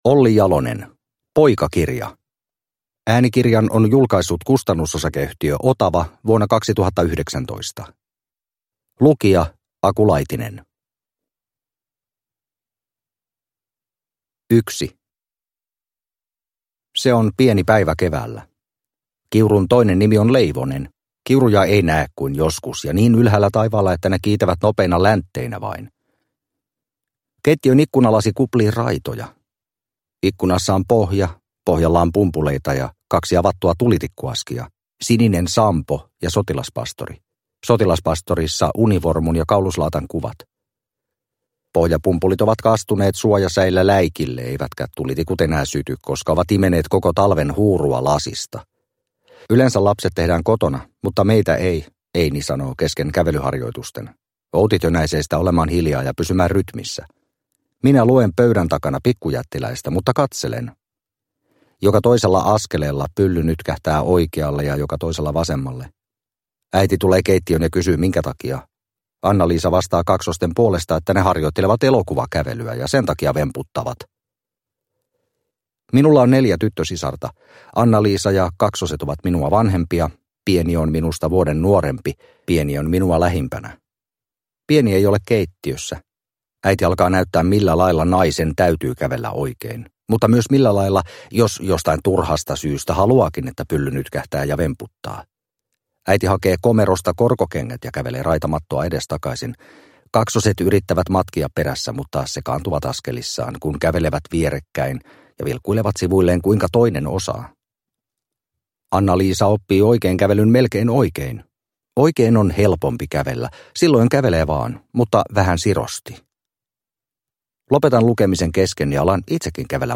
Poikakirja – Ljudbok – Laddas ner